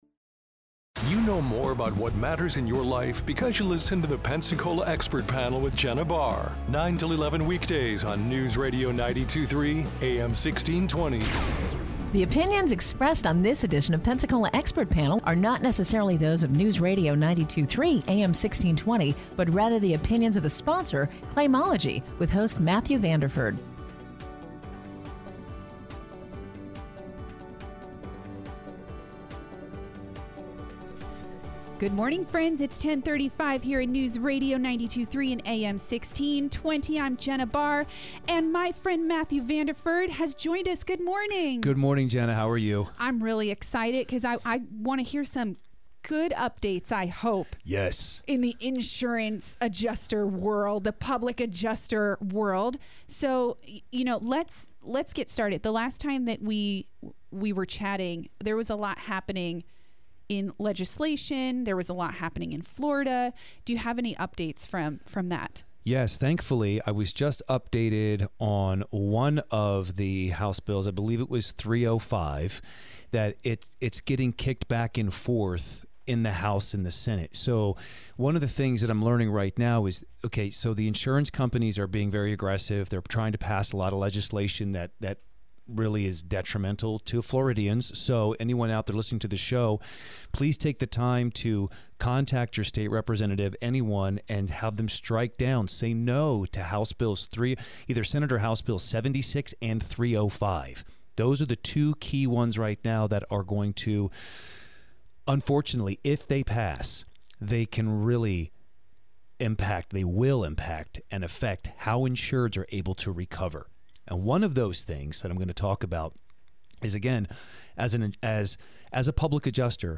A caller joins to share his experience with Claimology.